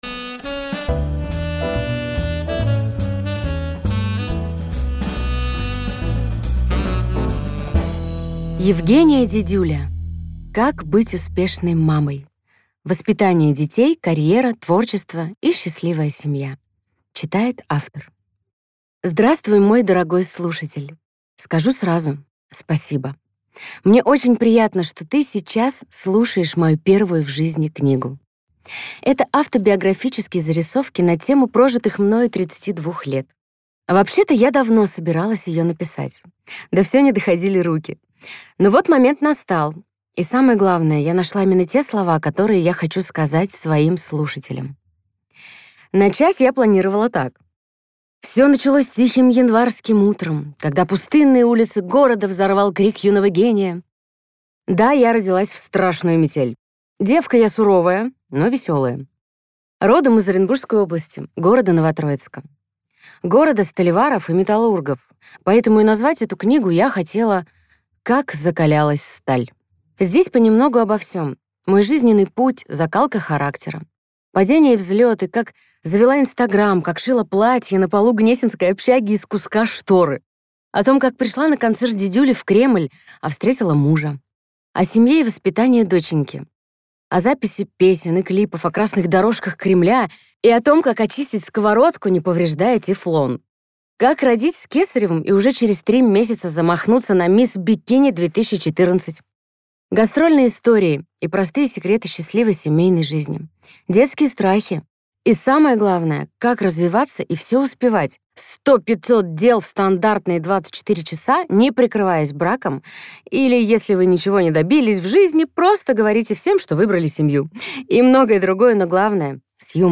Аудиокнига Как быть успешной мамой: воспитание детей, карьера, творчество и счастливая семья | Библиотека аудиокниг